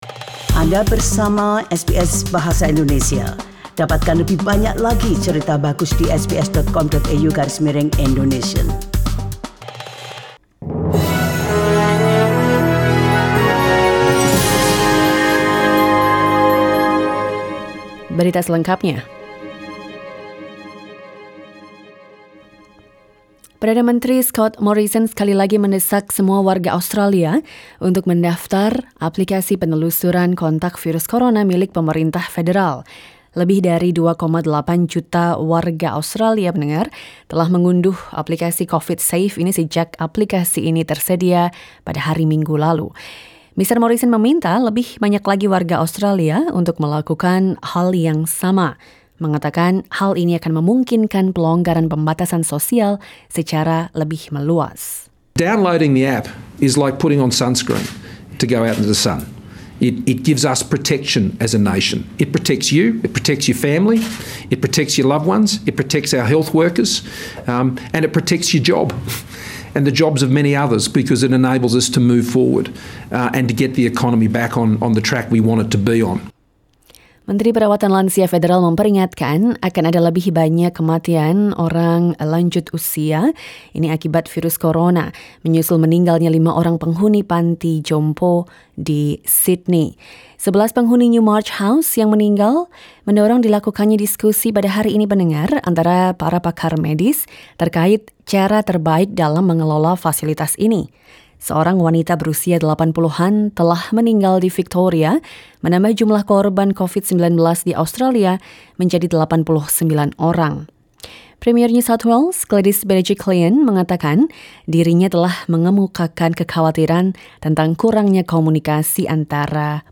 SBS Radio news in Indonesian, 29 April 2020.